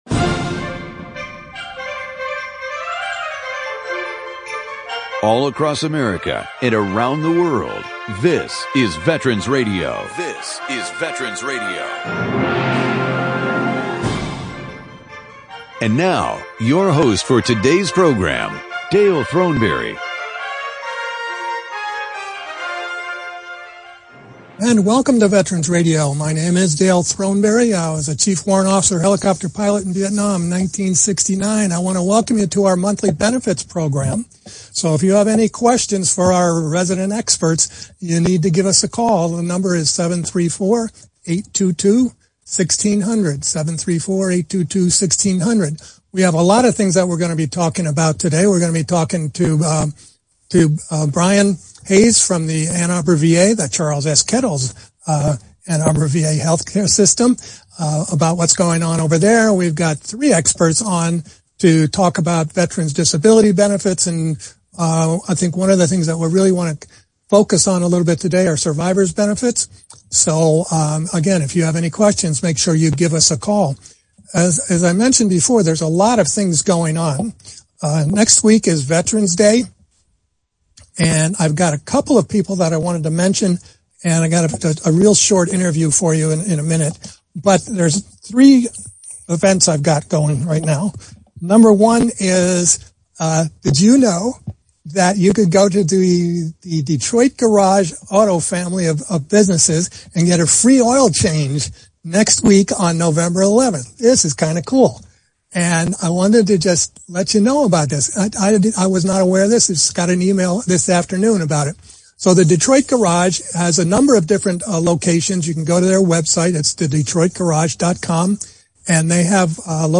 Monthly panel discussing US Veterans Benefits and recent changes, ways to improve outcome of disability and survivorship claims and PTS non-drug treatments.
Call in during the program to ask your question or offer a comment.